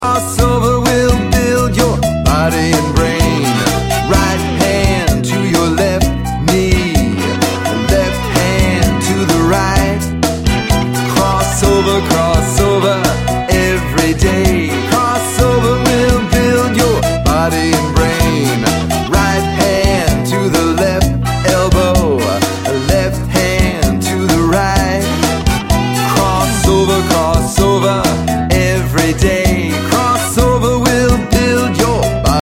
Song for Exercise and Fitness
Action Song Lyrics